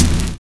FINE BD    5.wav